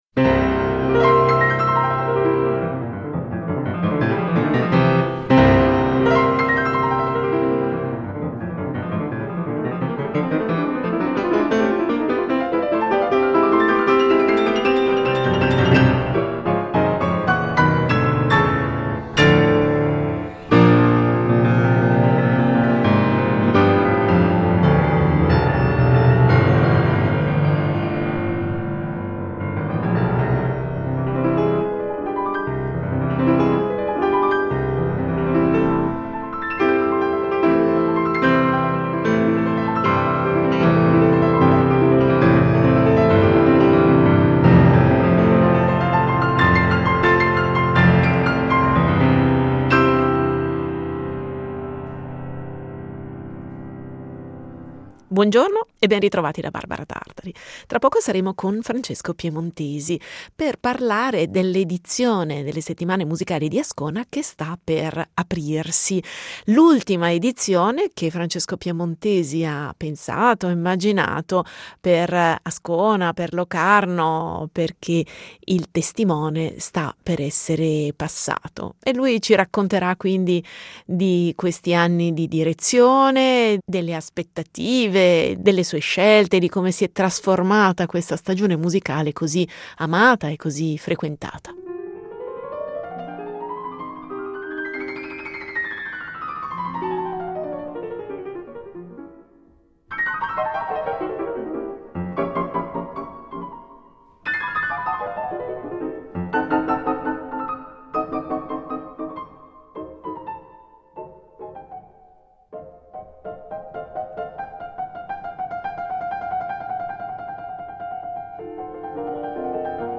Incontro con Francesco Piemontesi alla sua ultima edizione in veste di direttore artistico